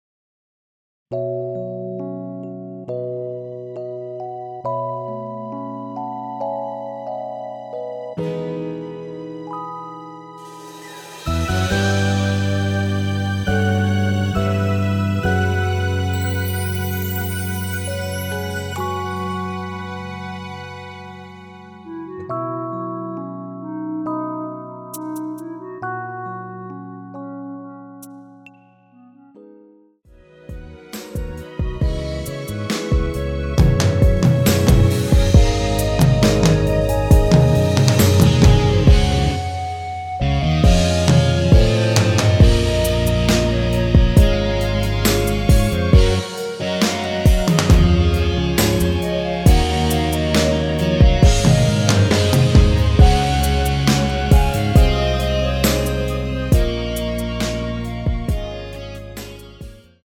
원키에서(-9)내린 멜로디 포함된 MR입니다.(미리듣기 확인)
앞부분30초, 뒷부분30초씩 편집해서 올려 드리고 있습니다.
중간에 음이 끈어지고 다시 나오는 이유는